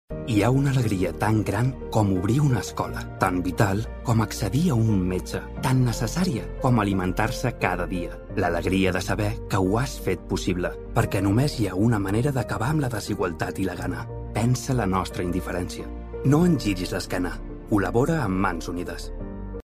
Male
Doku